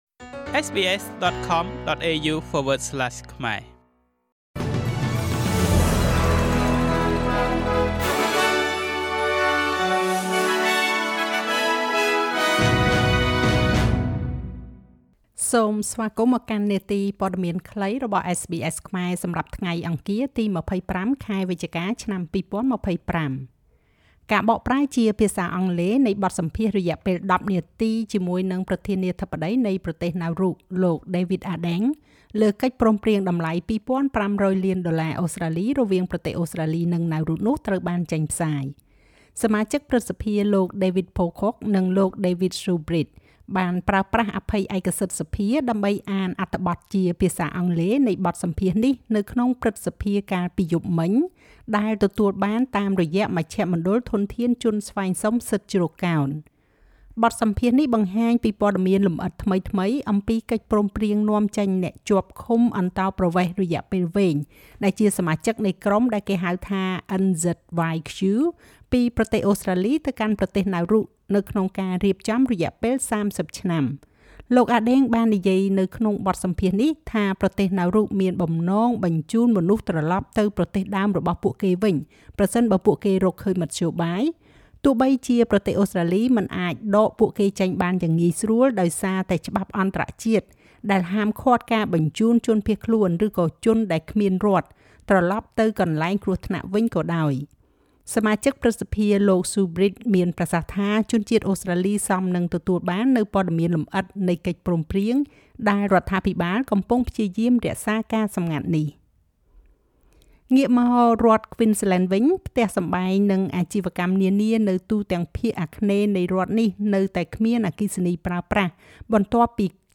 នាទីព័ត៌មានខ្លីរបស់SBSខ្មែរ សម្រាប់ថ្ងៃអង្គារ ទី២៥ ខែវិច្ឆកា ឆ្នាំ២០២៥